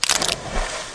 ship-sinking.wav